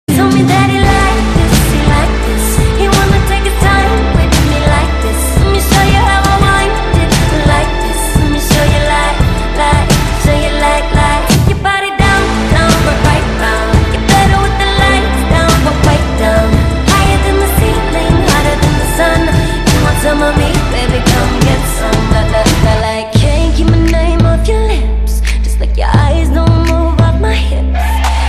M4R铃声, MP3铃声, 欧美歌曲 139 首发日期：2018-05-14 09:20 星期一